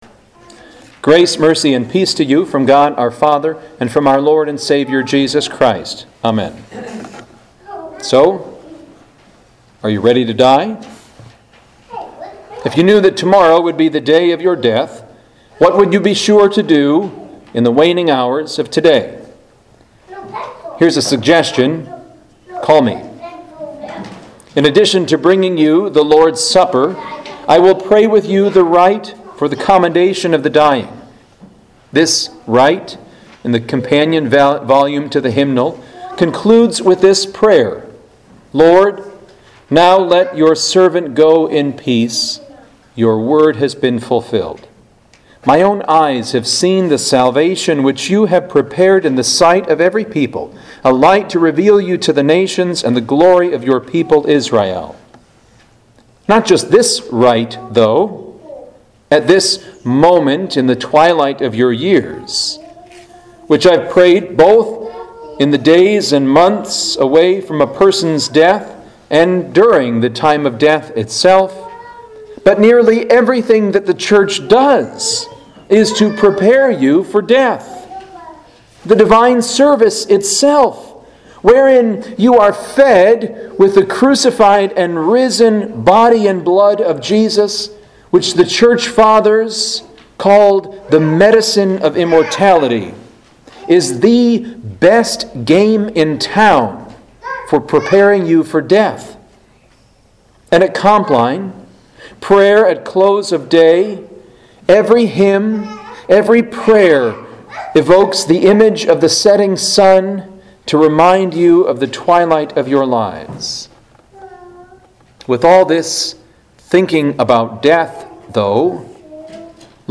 Home › Sermons › The Purification of Mary and the Presentation of Our Lord